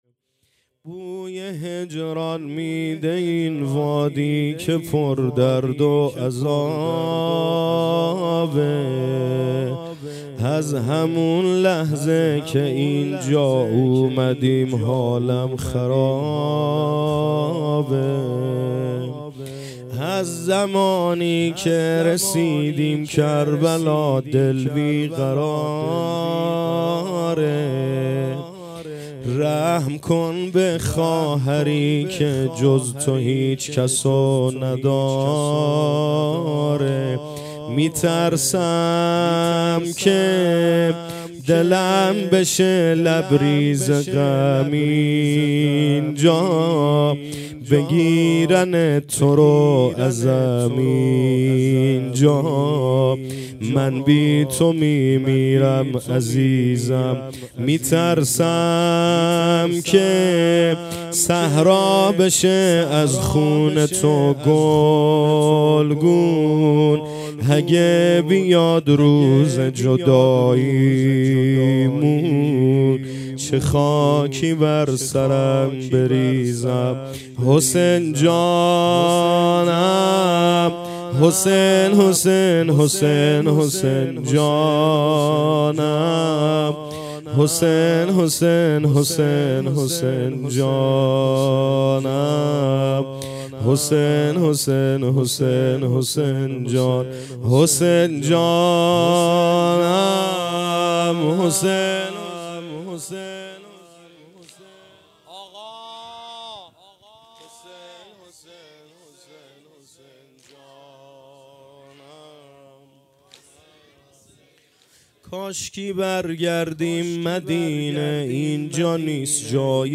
محرم ۱۴۰۲ - شب دوّم
باکین هیأت محبان حضرت زهرا علیهاالسلام زاهدان